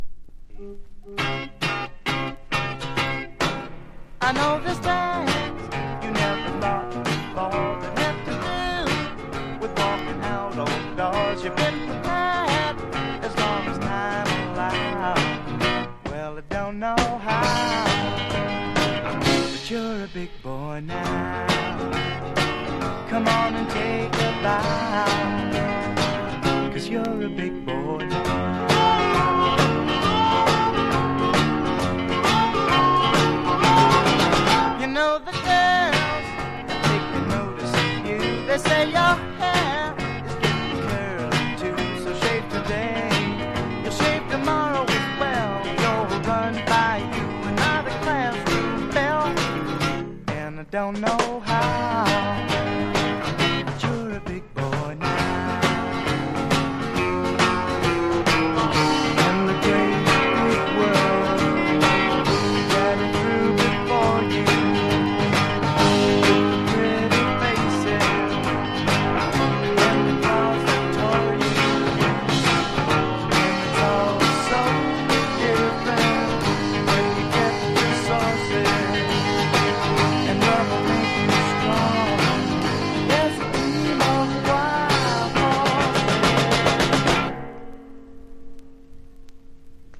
1. 60'S ROCK >
全体を通して非常にキャッチーかつポップで聴きやすく
VOCAL & POPS